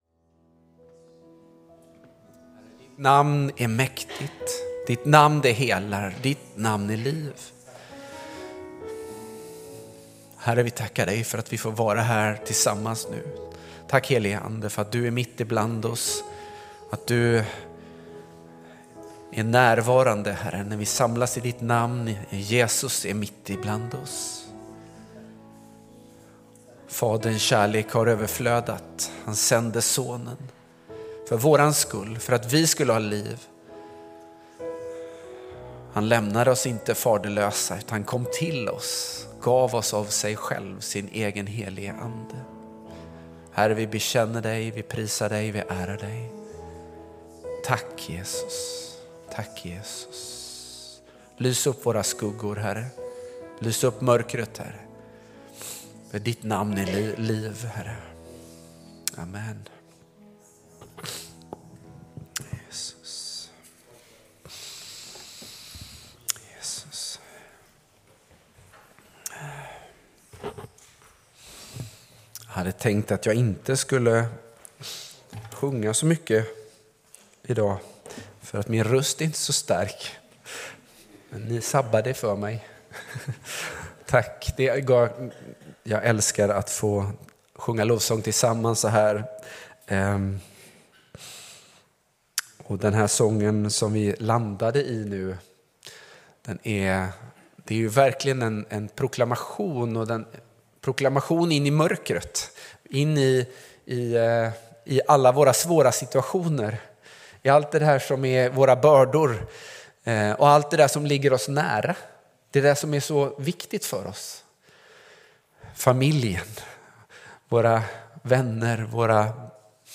Gemensam gudstjänst den 1 juni 10:30
Gudstjänst i Equmeniakyrkan Götene den 1 juni med predikan av